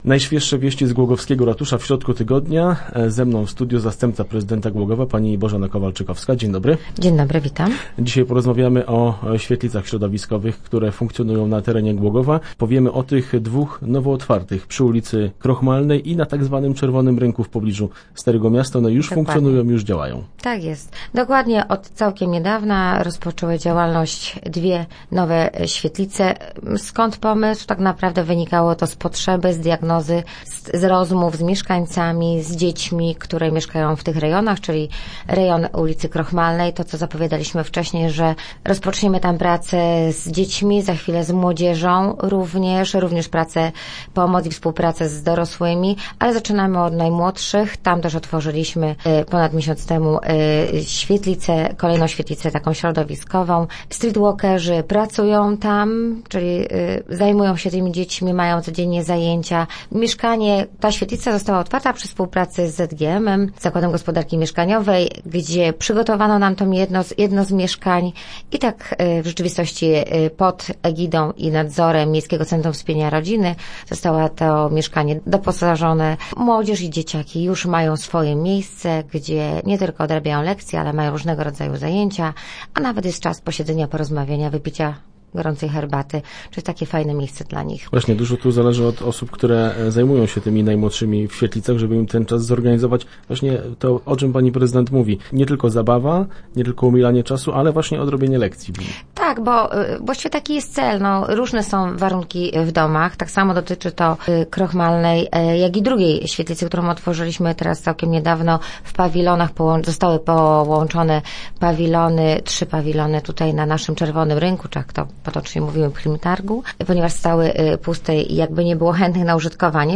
W styczniu swoją działalność rozpocznie kolejna tego typu placówka. Na ten temat rozmawialiśmy w studiu z zastępcą prezydenta miasta Bożeną Kowalczykowską.